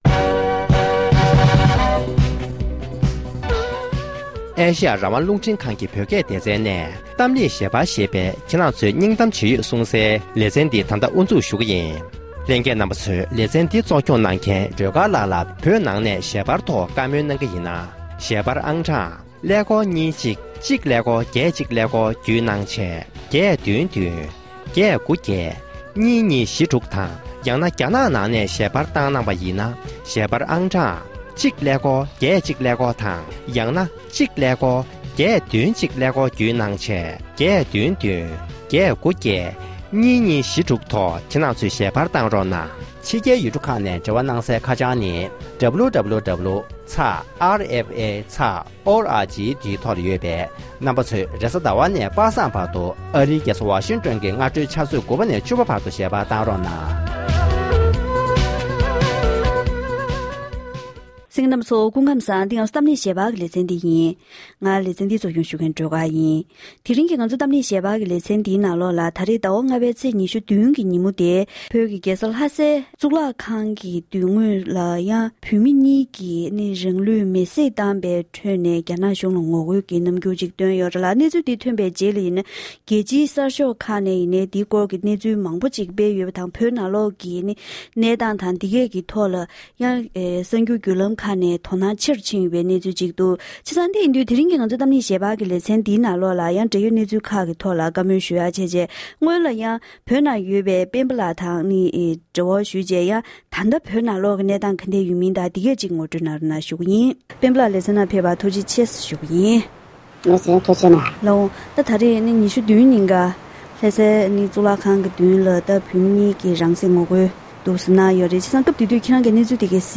གཏམ་གླེང་ཞལ་པར
ལྷ་ས་ནས་བོད་མི་ཞིག་གིས